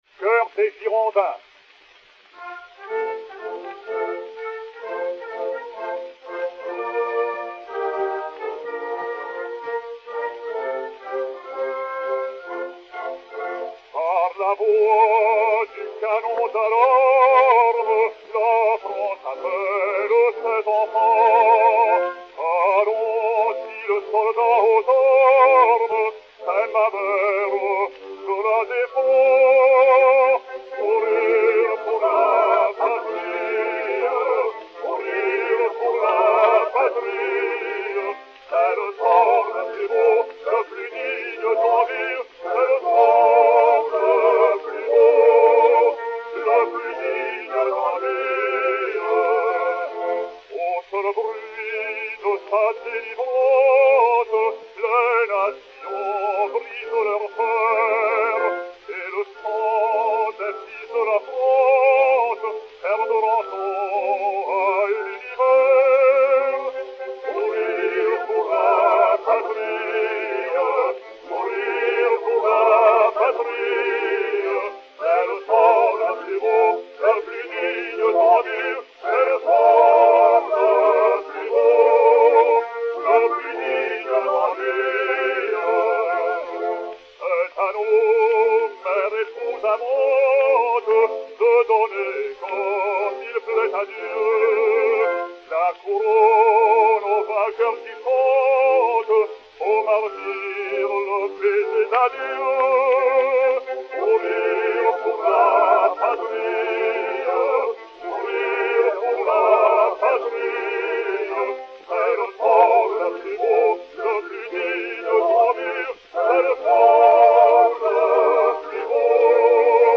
basse française
Chœurs et Orchestre